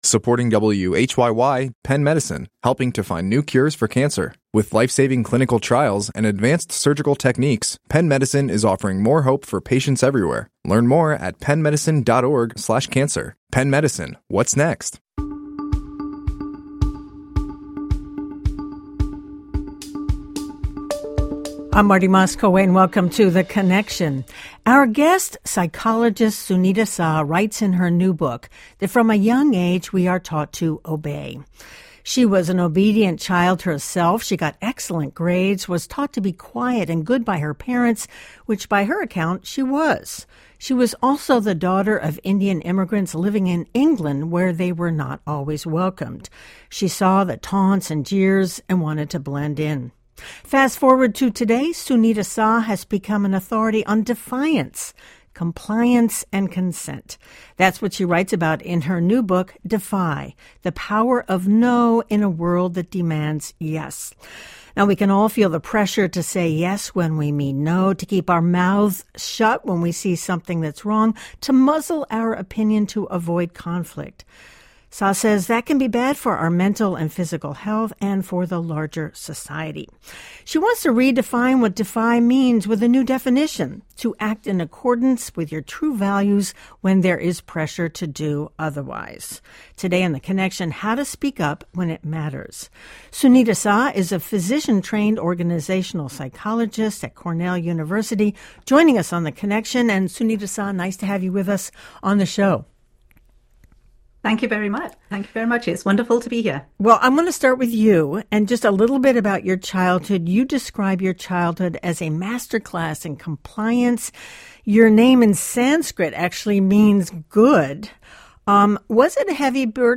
On the first day of spring, we invite two master gardeners to answer your questions about planting, growing, weeding, mulching and more.